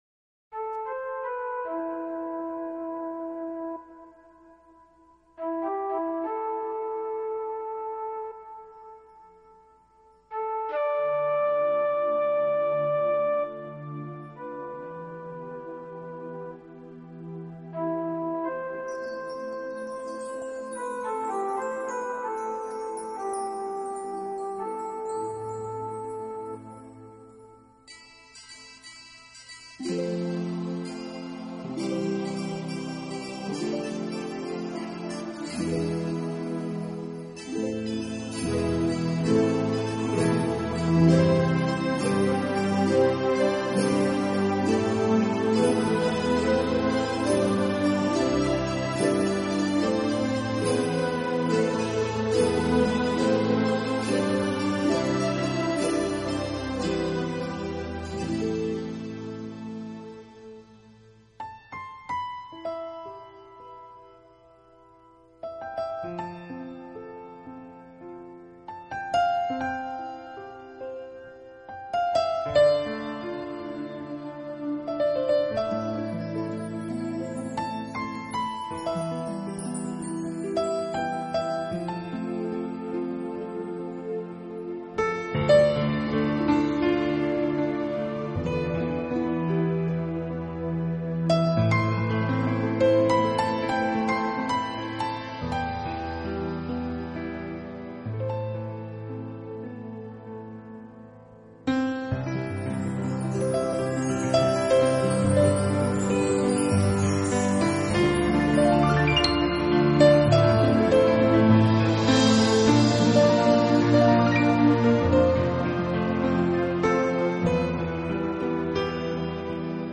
【新世纪钢琴】
音乐风格: Newage